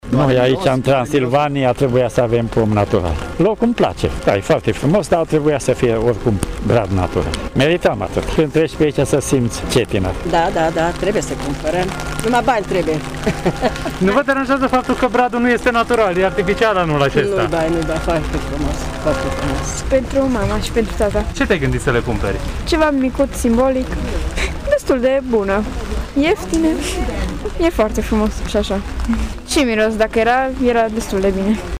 Chiar dacă azi condițiile meteorologice de la Târgu Mureș nu au ținut cu cetățenii, aceștia au ieșit deja să viziteze și să cumpere din târg. Ei se arată mulțumiți de cele văzute, dar au mici rezerve legate de bradul de Crăciun: